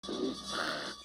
File:Giant Antlion Roar Fixed.mp3
Giant_Antlion_Roar_Fixed.mp3